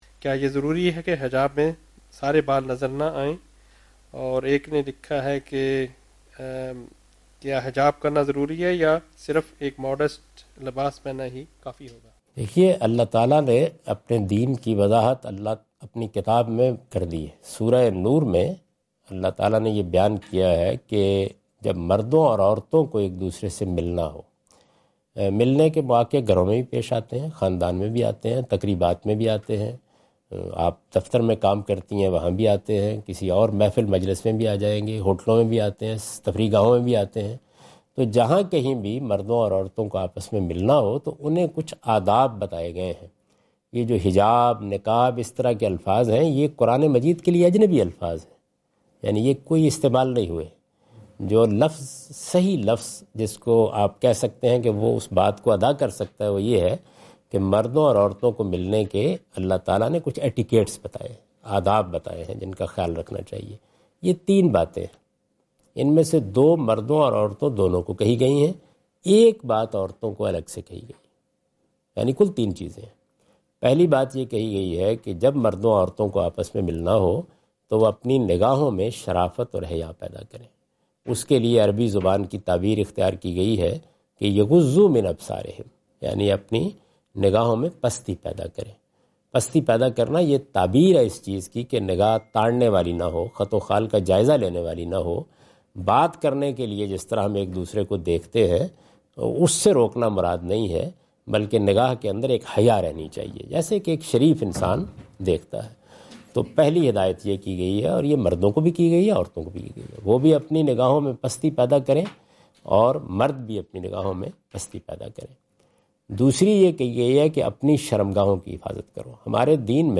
Javed Ahmad Ghamidi answer the question about "Is it necessary to cover hair with Hijab?" during his Australia visit on 11th October 2015.